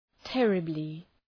{‘terəblı}